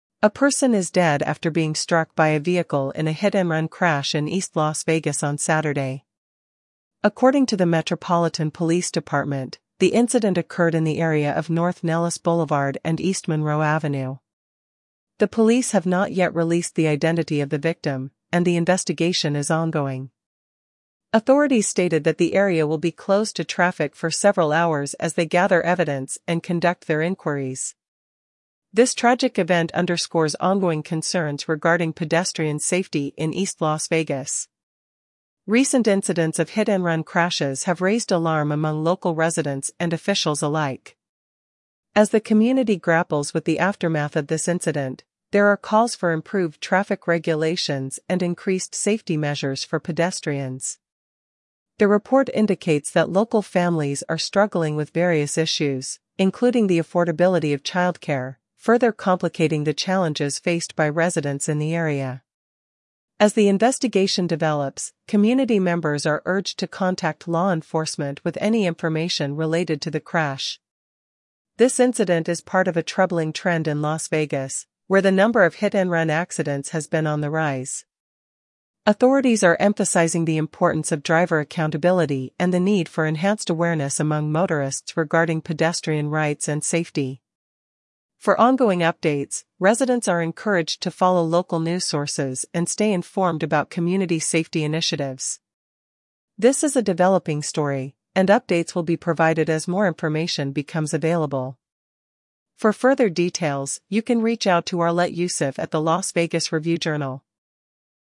Regional News